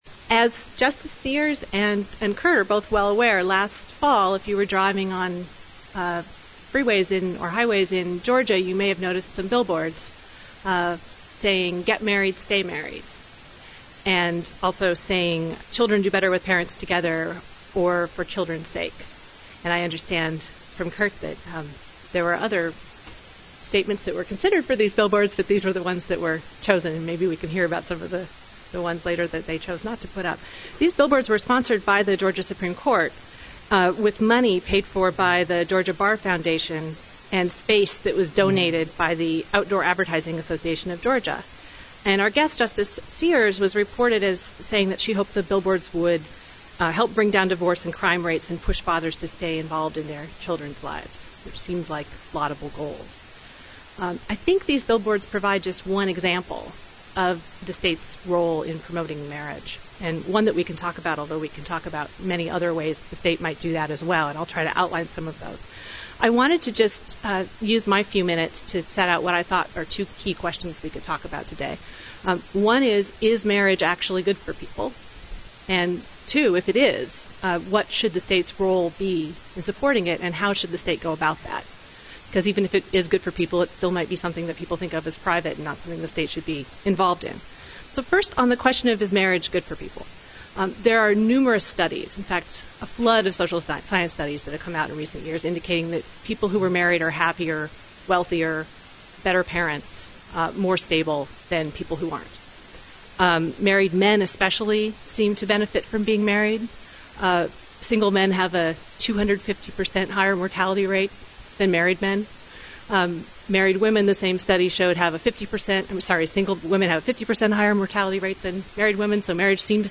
marriage_panel.mp3